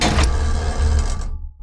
gun-turret-activate-03.ogg